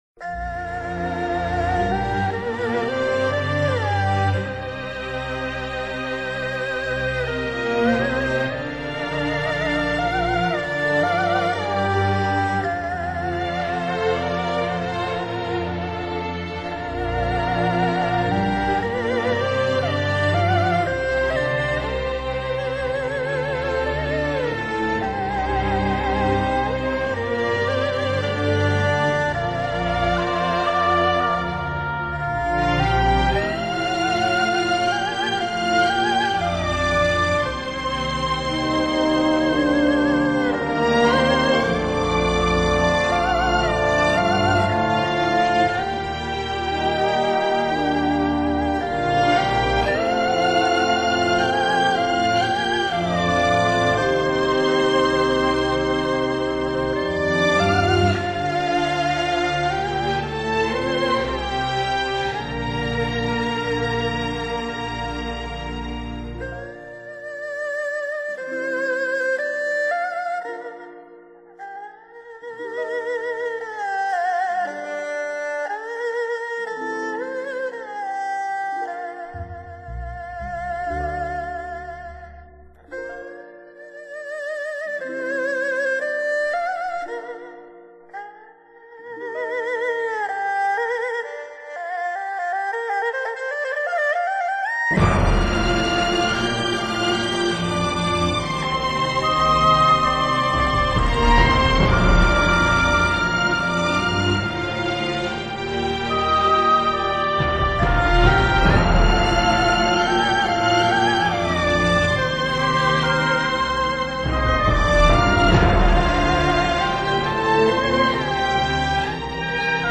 交响乐、民乐、电子音乐高度融合
旋律优美、曲式传统、配器多彩，甚至掺进了流行的元素
音乐是缓慢而平和的，甚至有点温暖和甜蜜